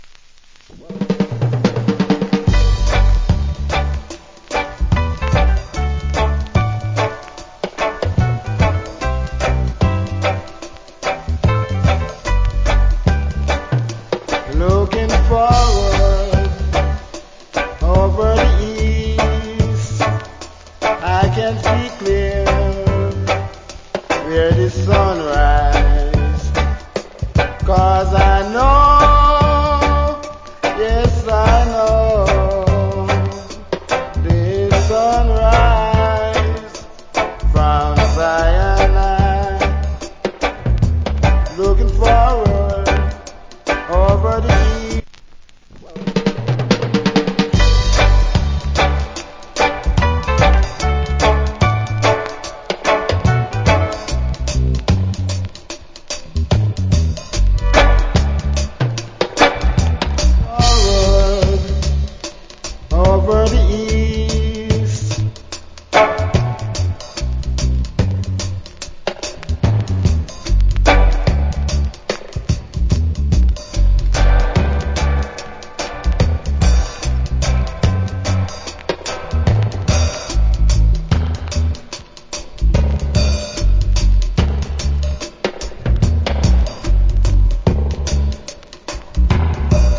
Nice Roots Rock Vocal.